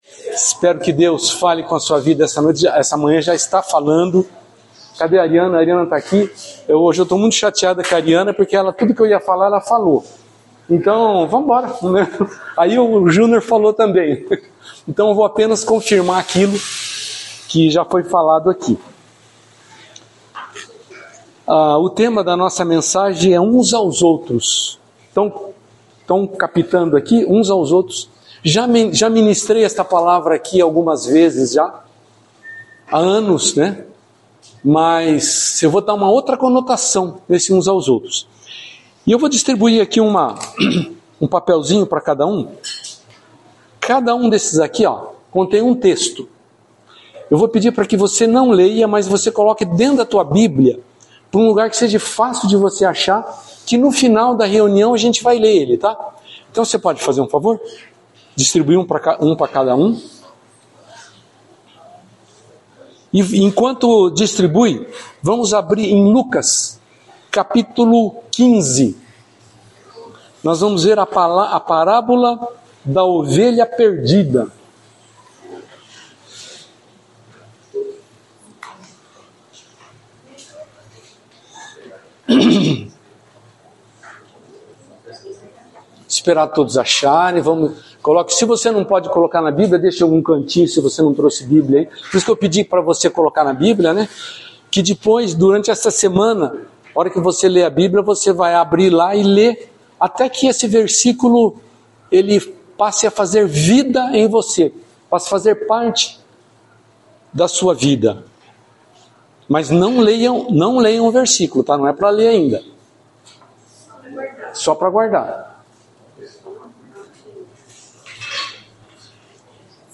Palavra ministrada
no culto do dia 02/02/2025 – Tema: Uns aos outros.